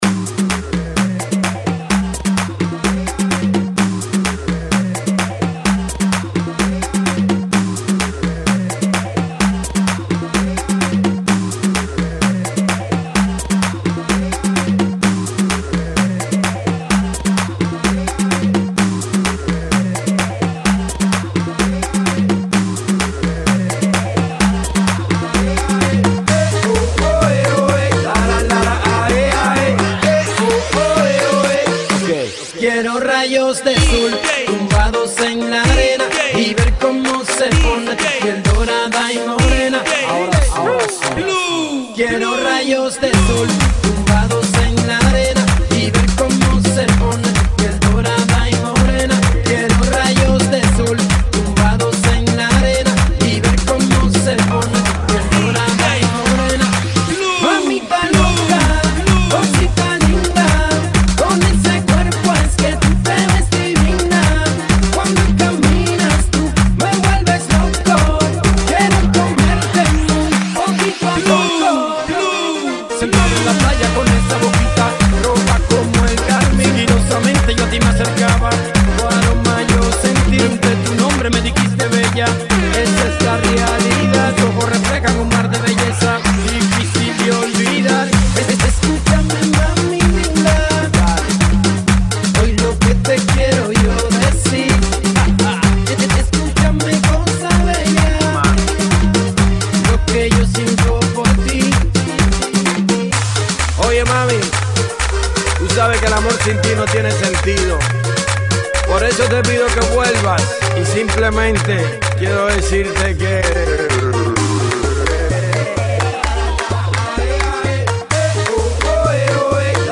128 Bpm